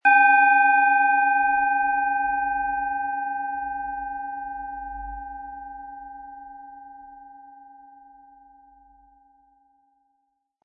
Planetenschale® Erfinde Dich neu & Sei spontan und unbefangen mit Uranus, Ø 11,6 cm, 100-180 Gramm inkl. Klöppel
Planetenton 1
Nach uralter Tradition von Hand getriebene Uranus Planetenschale.
Den passenden Klöppel erhalten Sie umsonst mitgeliefert, er lässt die Schale mit dem Planetenton Uranus voll und wohlklingend klingen.
Das Klangbeispiel spielt Ihnen den Original-Ton der hier angebotenen Schale vor.
MaterialBronze